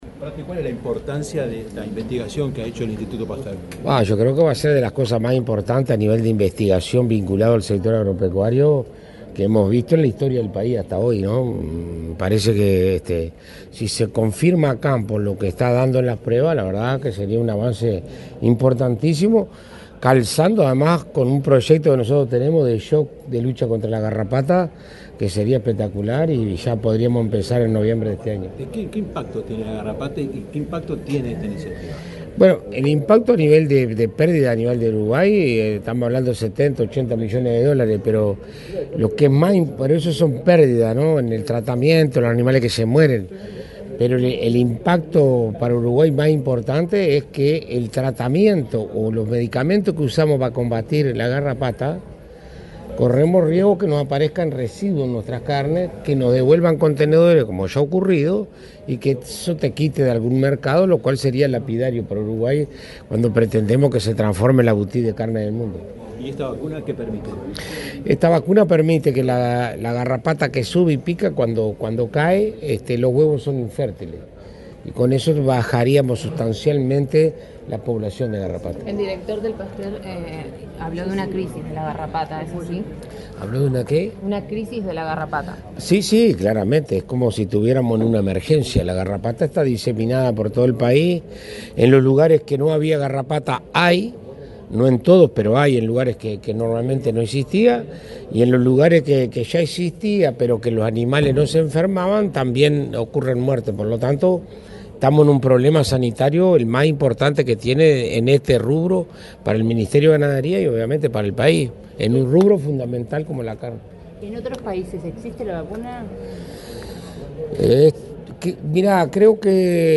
Declaraciones del ministro de Ganadería, Alfredo Fratti
Declaraciones del ministro de Ganadería, Alfredo Fratti 07/05/2025 Compartir Facebook X Copiar enlace WhatsApp LinkedIn Este miércoles 7 en el Club de Golf de Montevideo, el ministro de Ganadería, Alfredo Fratti, dialogó con la prensa, luego de participar en la presentación de una vacuna contra la garrapata, desarrollada por el Institut Pasteur de Montevideo.